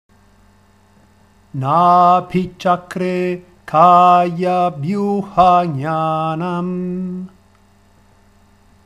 Vibhuti Padah canto vedico